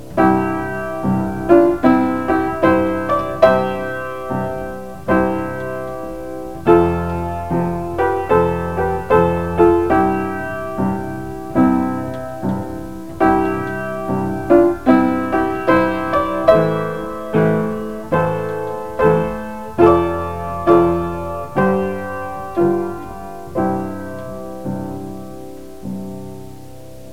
1 channel
hymnchor.wav